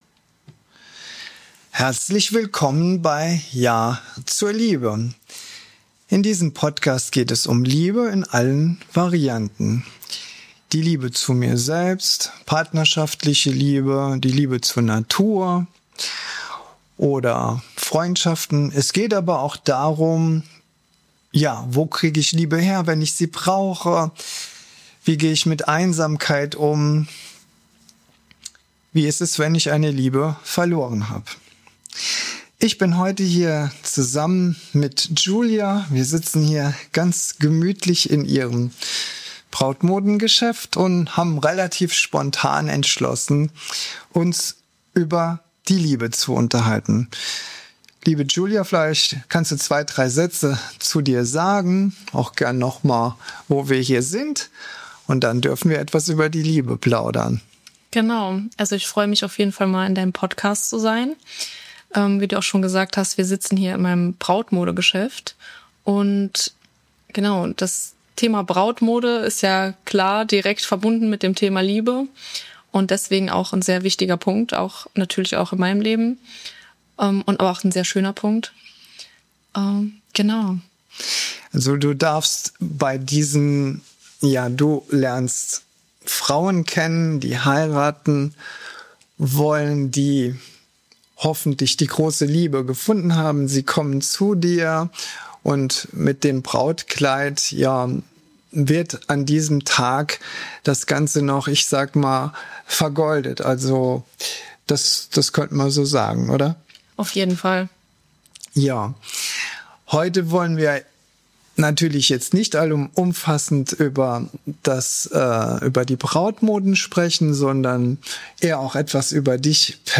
In diesem Gespräch